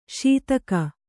♪ śitaka